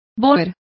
Complete with pronunciation of the translation of boers.